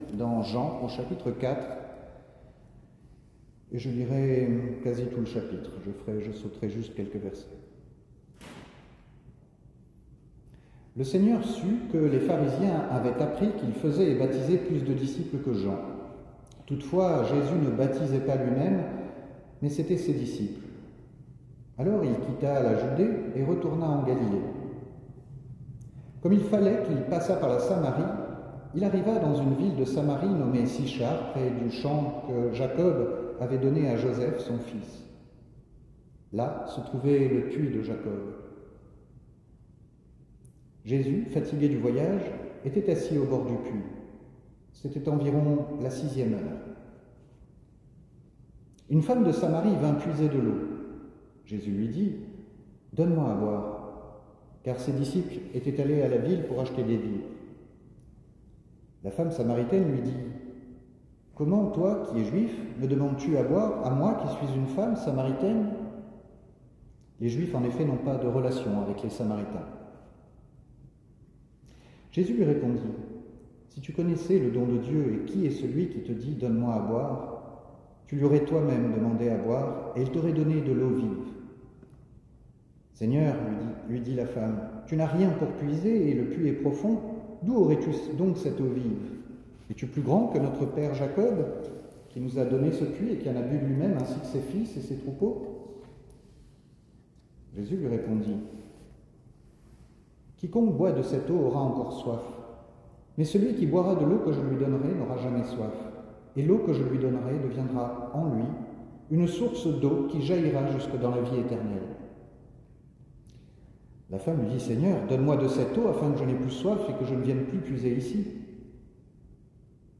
Enregistrement audio de la prédication